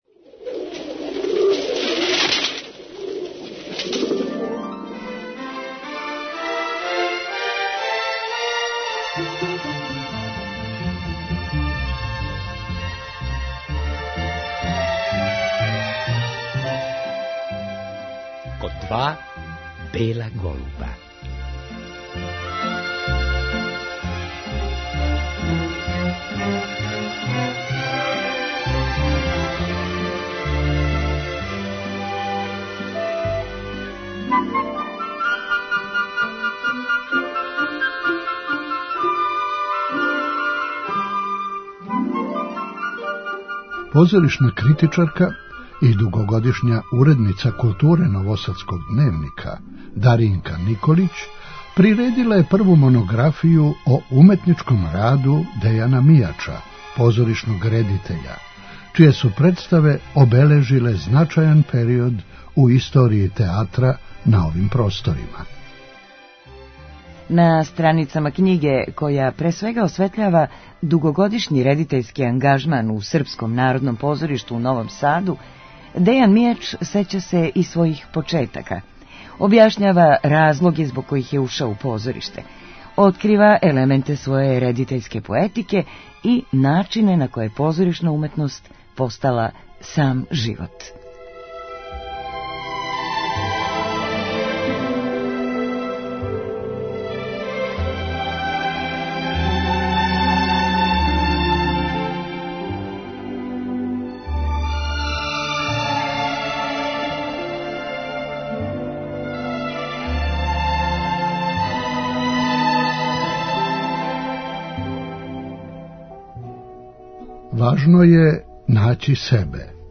У емисији „ Код два бела голуба", Дејан Мијач, један од најзначајнијих редитеља југословенског и српског театра, говори о томе како је пронашао себе у позоришту, о тајнама режије и Београду који је заволео још у студентским данима.